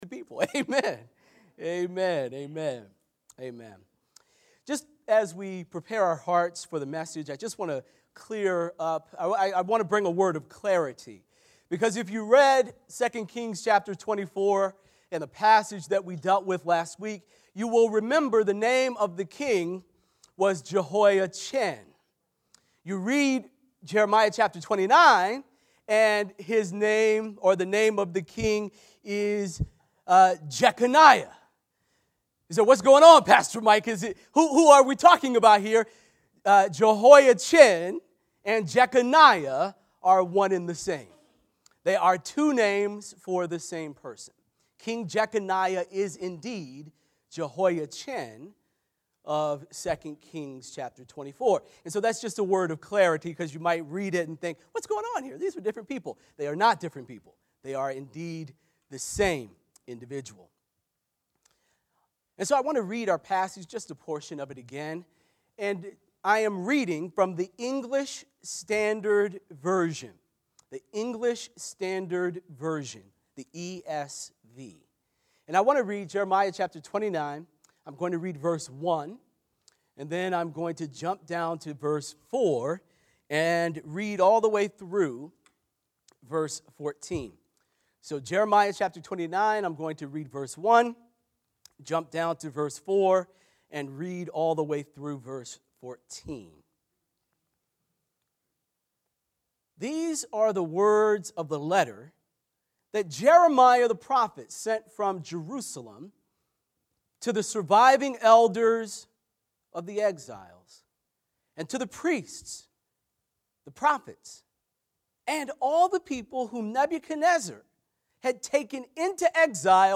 Crossroads Church of Hillside Sermons